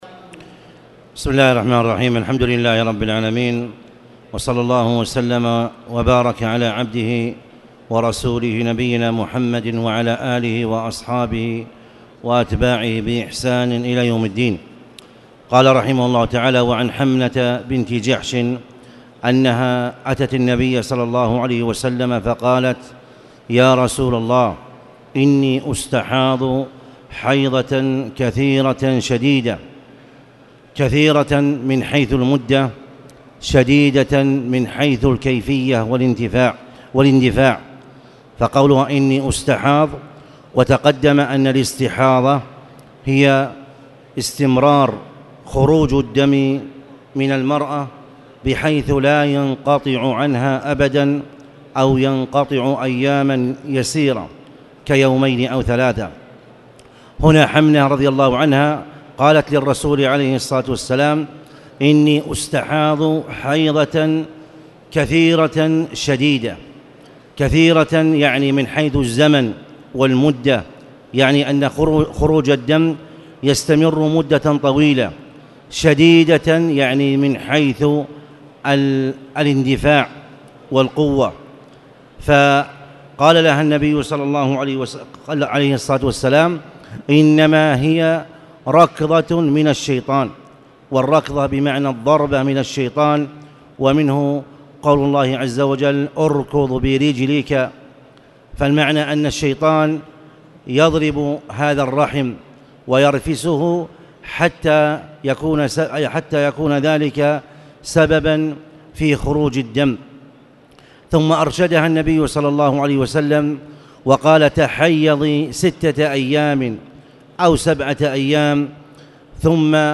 تاريخ النشر ٢٥ جمادى الأولى ١٤٣٨ هـ المكان: المسجد الحرام الشيخ